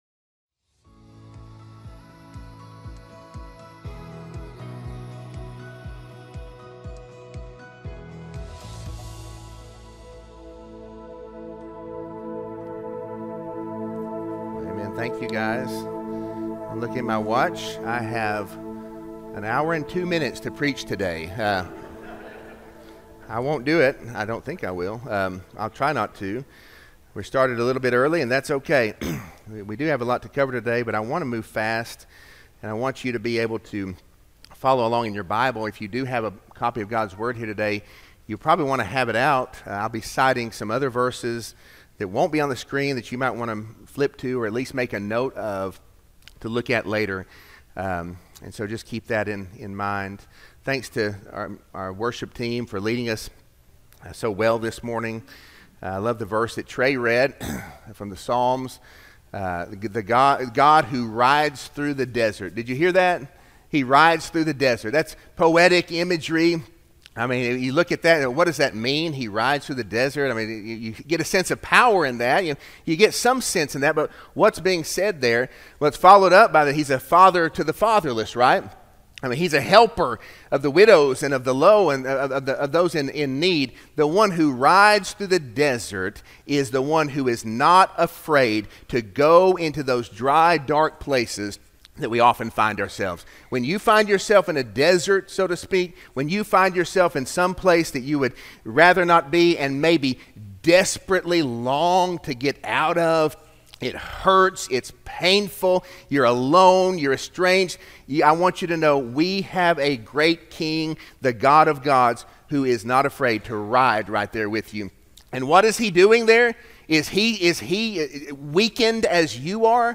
Sermon-7-20-25-audio-from-video.mp3